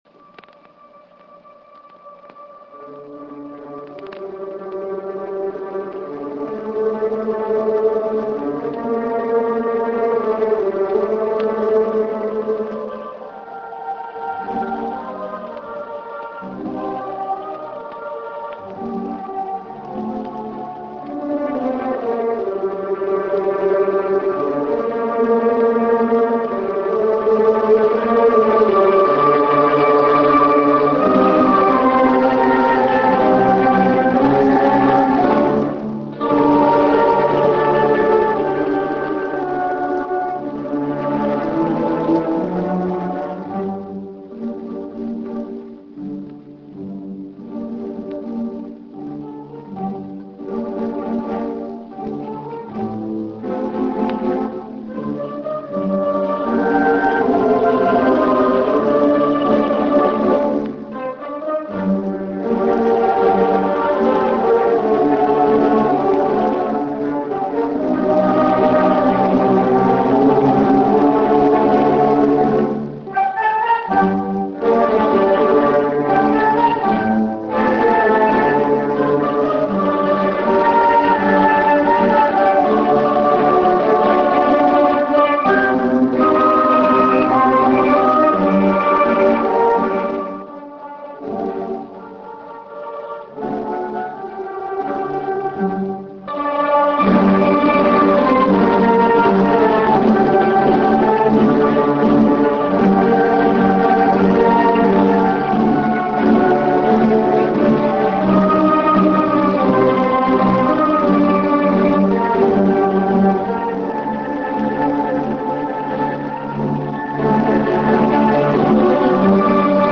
ＫＧＭＣ創立５０周年第４２回定期演奏会
１９６７年１１月２５日 at Festival Hall in OSAKA